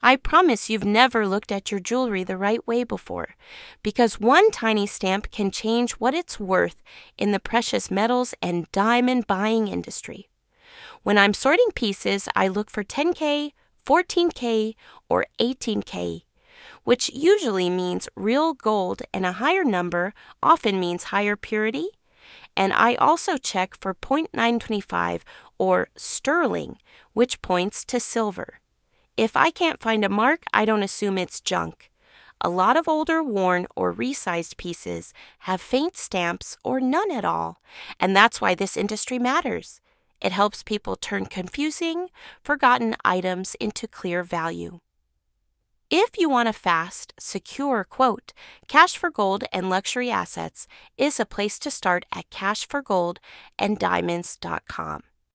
Voiceover video teaching viewers how to find common stamps/marks (10K, 14K, 18K, .925/sterling) and what they generally mean for value—plus reassurance that ...